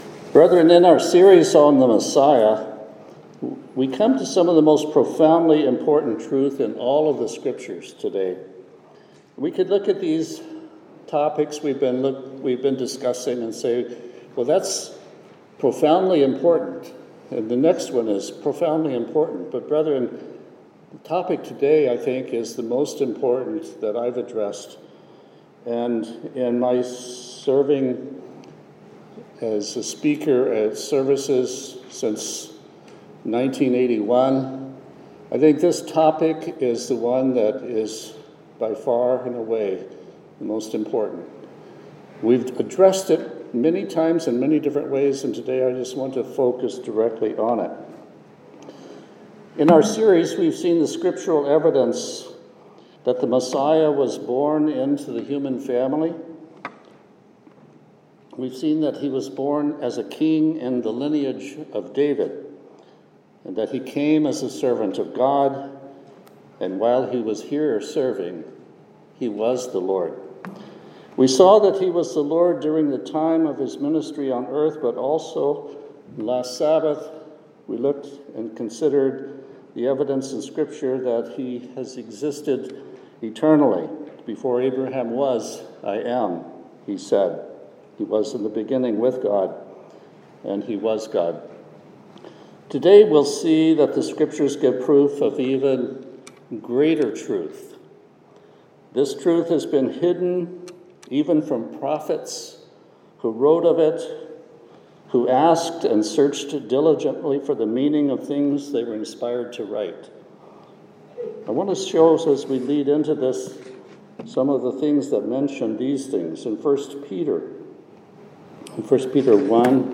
In this sermon we'll see the evidence of prophecies fulfilled--prophecies of the Messiah's Father.